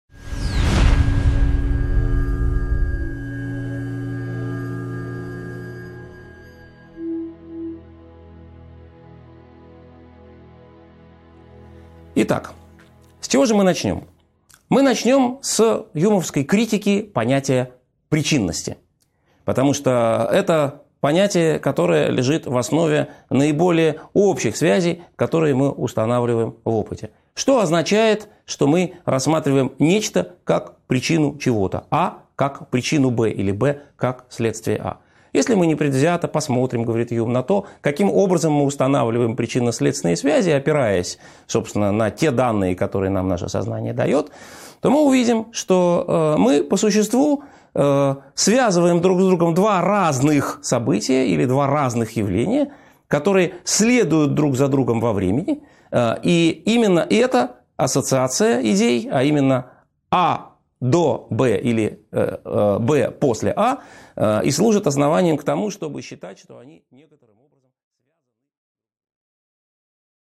Аудиокнига 10.3 Скептические аргументы против рационализма и эмпиризма (продолжение) | Библиотека аудиокниг